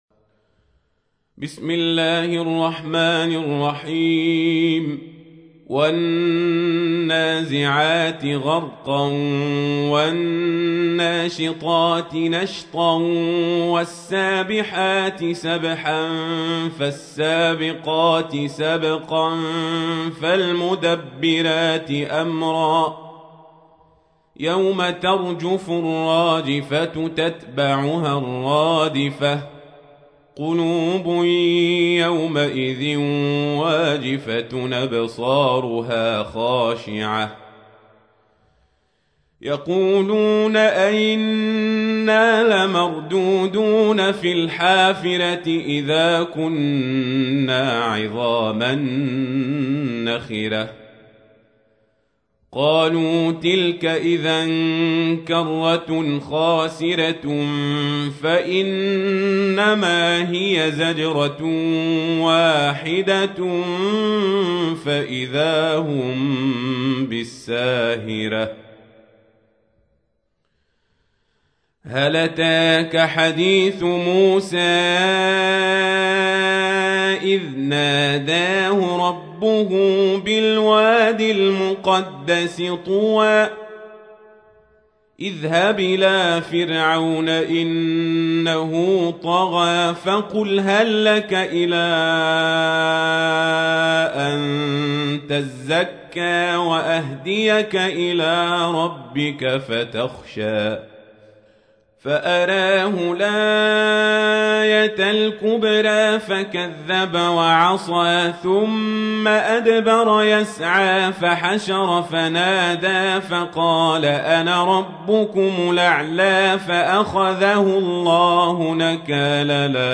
تحميل : 79. سورة النازعات / القارئ القزابري / القرآن الكريم / موقع يا حسين